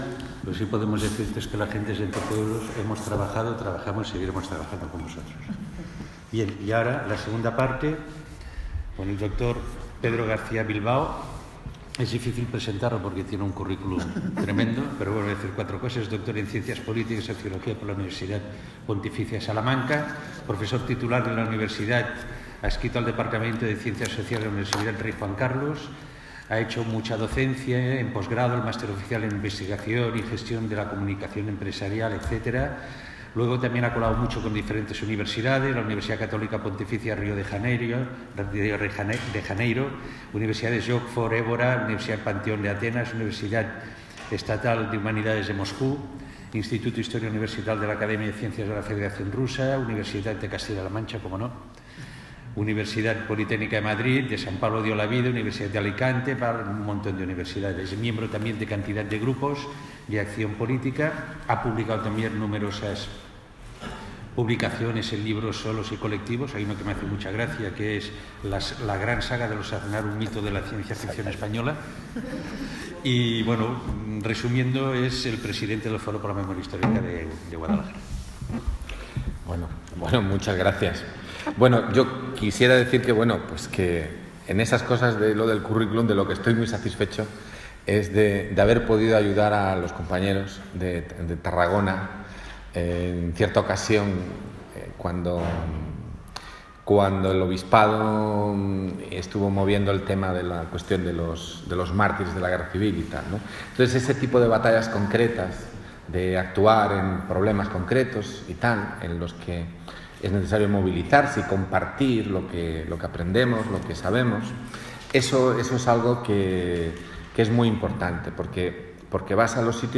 Audio del acto celebrado el 29 de noviembre de 2016